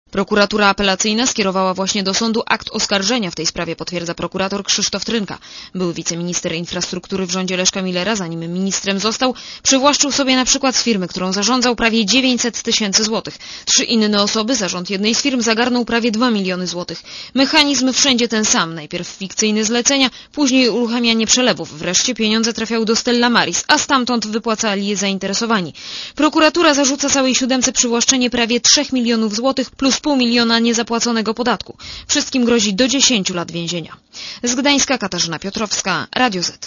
Relacja reporetera Radia Zet